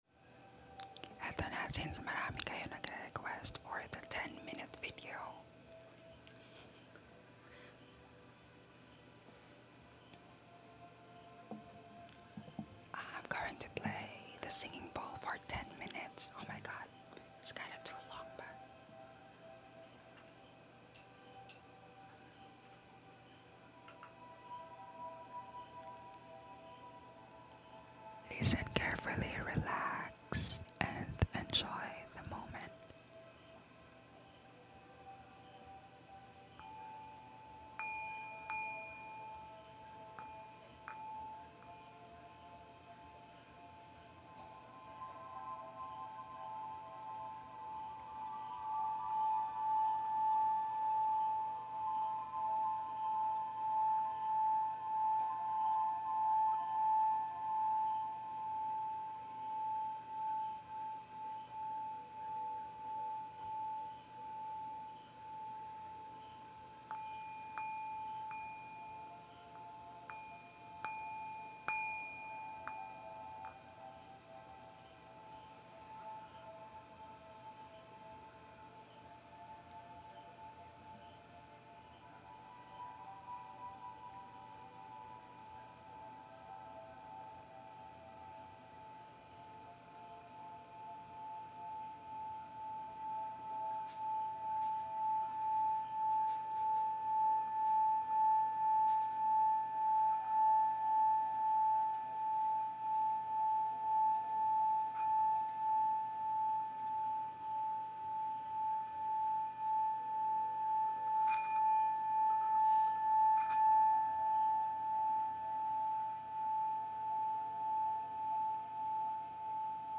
ASMR 10 MINUTES PLAYING SINGING